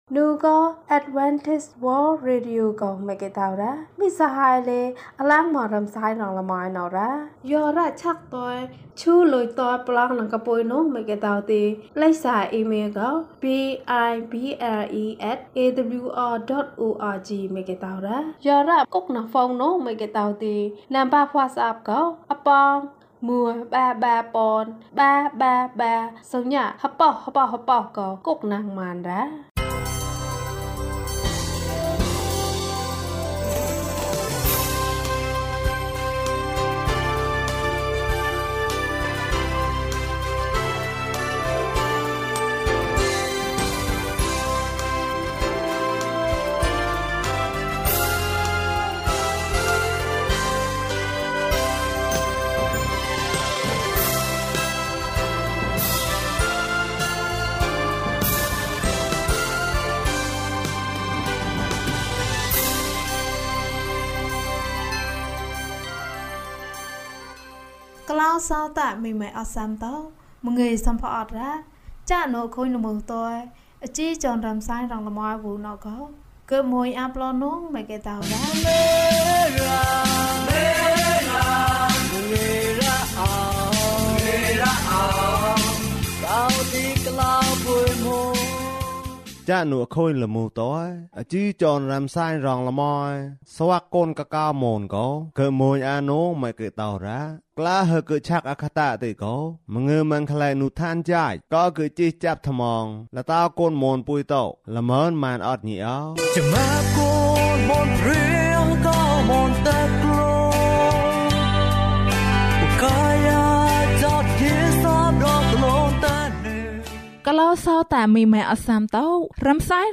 သခင်ယေရှုကို အရင်ပြောပါ။ ကျန်းမာခြင်းအကြောင်းအရာ။ ဓမ္မသီချင်း။ တရားဒေသနာ။